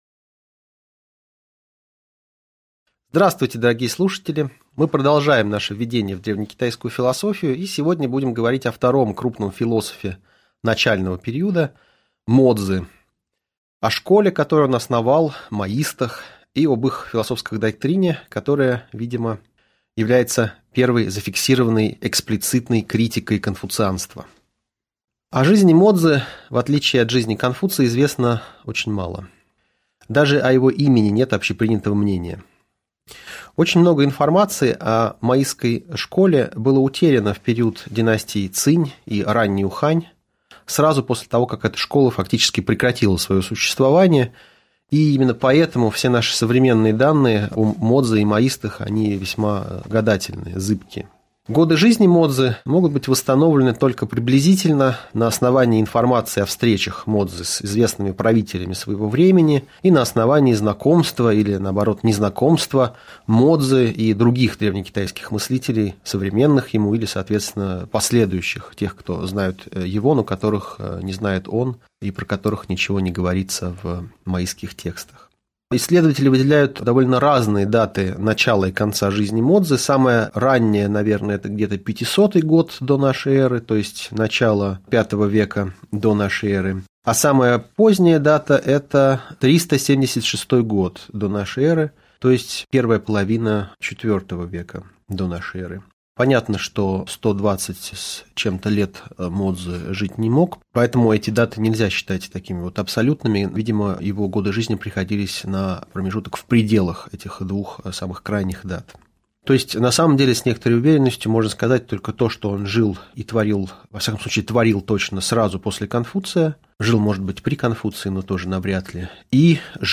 Аудиокнига Лекция «Мо-цзы и моисты. Часть 1» | Библиотека аудиокниг